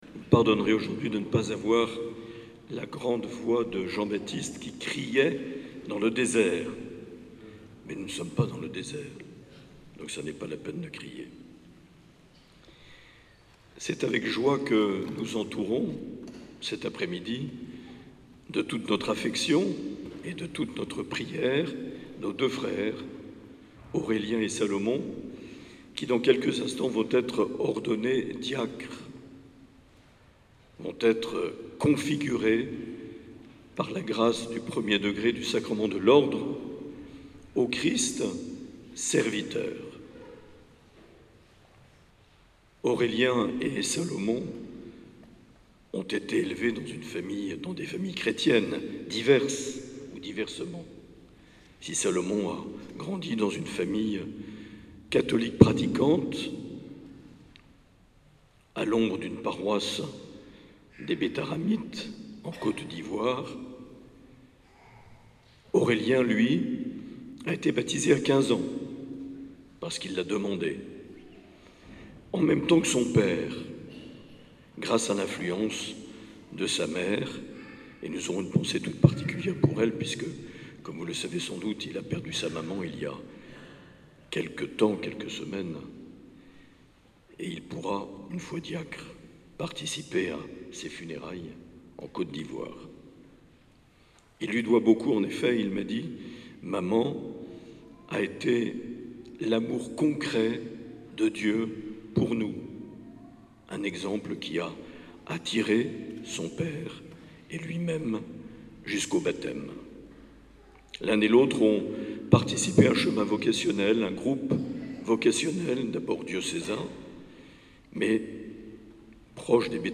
Les Homélies du 12 janv.
Une émission présentée par Monseigneur Marc Aillet